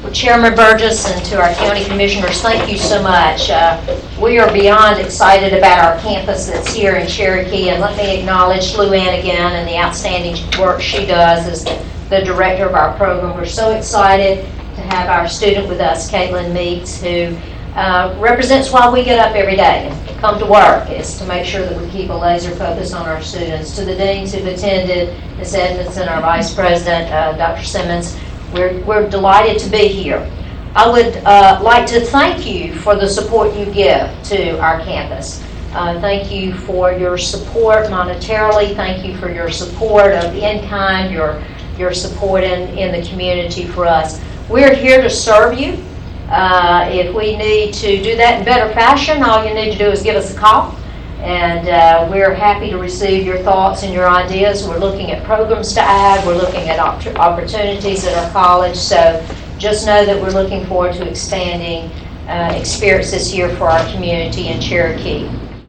During the Monday morning meeting of the Cherokee County Commission, a number of representatives were on hand from Gadsden State Community College as April was declared “National Community College Month” by the Commission.